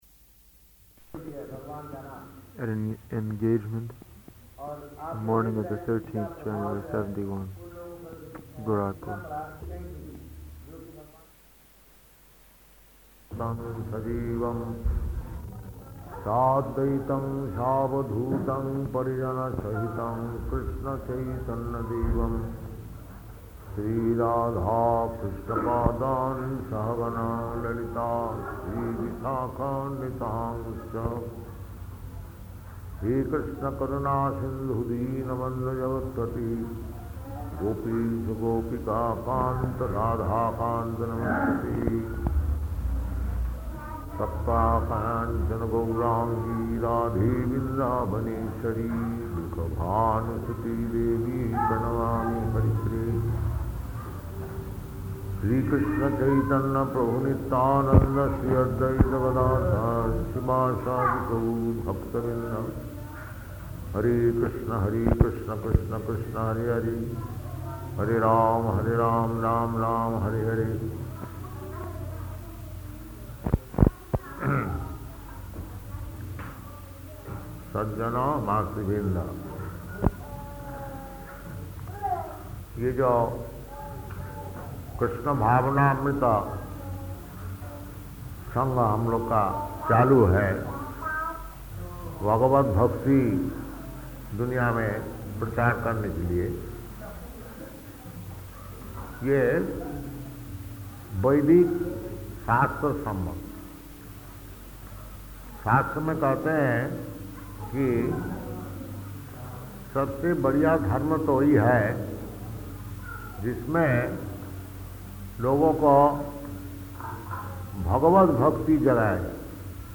Type: Lectures and Addresses
Location: Gorakphur